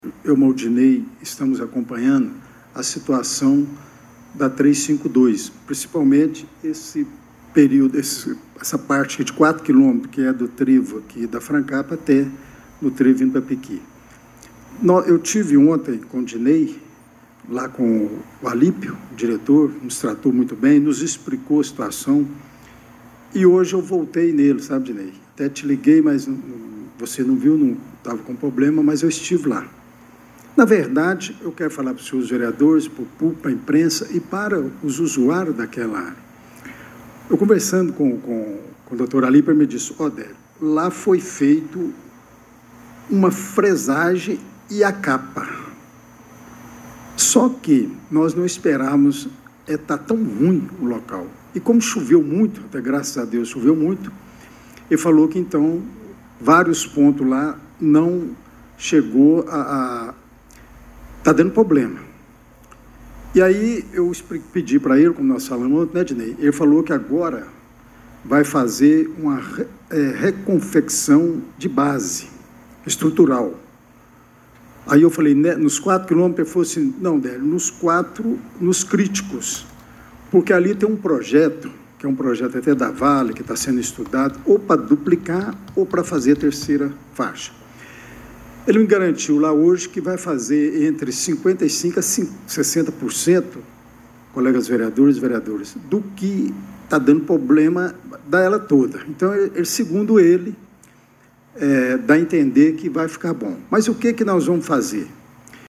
Vereador admite em pronunciamento na tribuna que obra na BR 352 “infelizmente não atendeu o que a gente esperava” – Portal GRNEWS
A reunião ordinária da Câmara Municipal de Pará de Minas, realizada nesta terça-feira, 24 de março, trouxe à tona o descontentamento com a qualidade das obras de infraestrutura rodoviária no município.